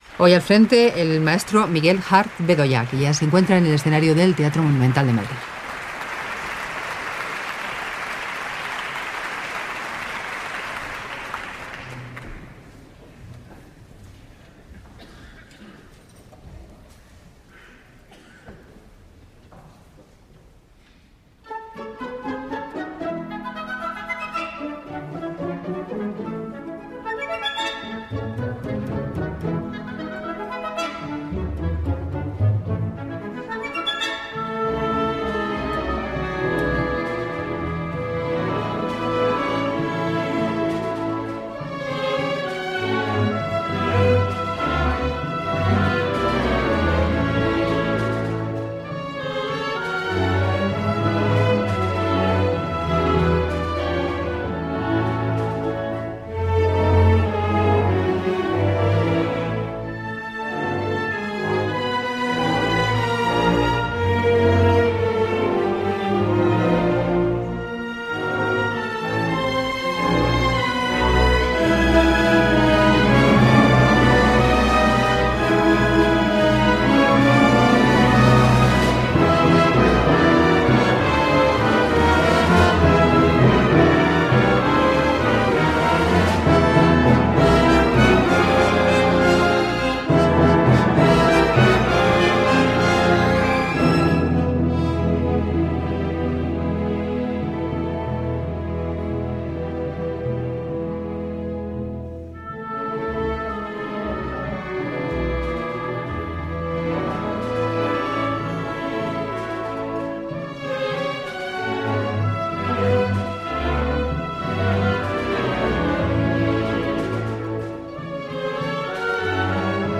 Live in Concert